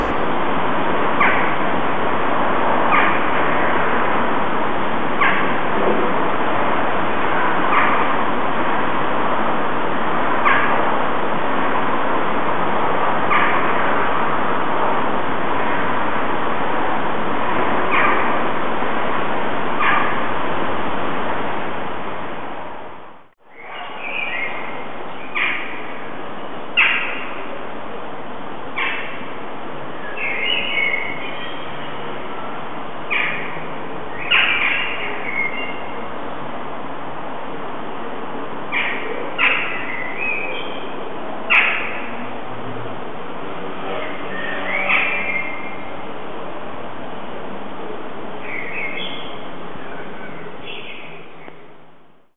Zumal obiges Foto an diesem Tag entstanden ist, wie auch eine weitere Tonaufnahme aus unserem Hinterhof: Den ich gerne mag: Gedämpft durch die Mauer des vorgelagerten Hauses ist das stete Rauschen der Vahrenwalder zu hören und die Straßenbahn: Auf dem Balkon blüht jedoch der Salbei, und Katzen teilen sich das Revier.
dohlen-im-hinterhof-vermutl-2009-1.mp3